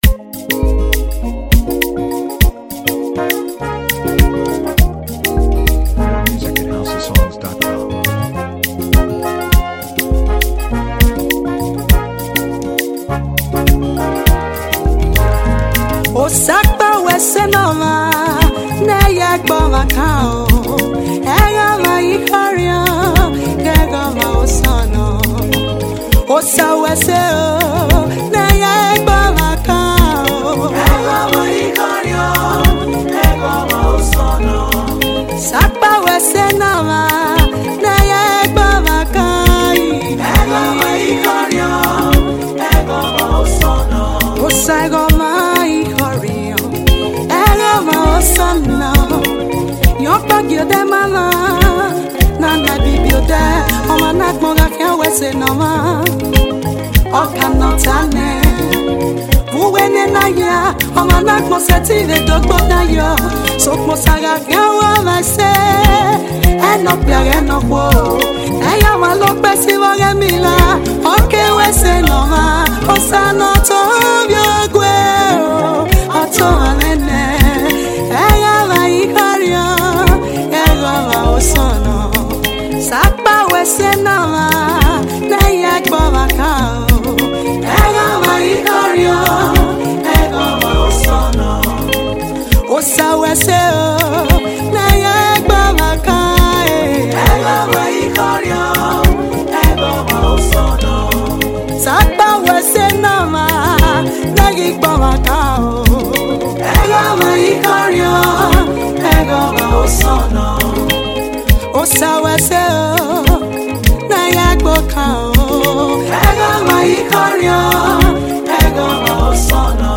• Gospel